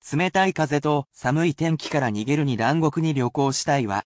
They are at a regular pace which may be difficult.
[casual speech]